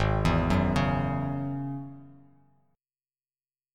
G#7sus4#5 chord